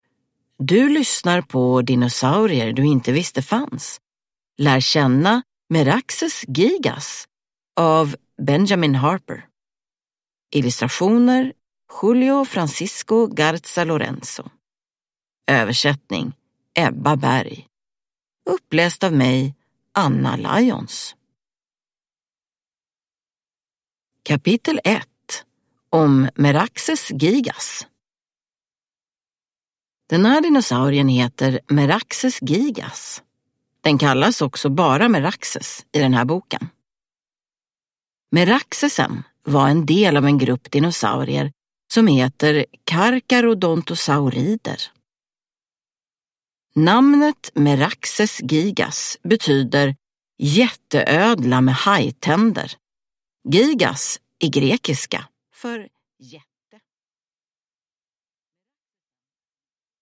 Lär känna Meraxes Gigas – Ljudbok